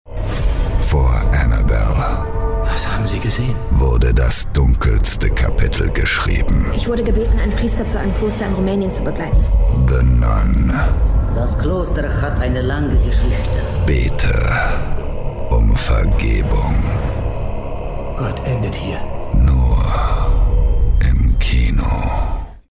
Dank seiner angenehmen sonoren Tonlage kommt er bei den Kunden immer hervorragend an.
Kino Trailer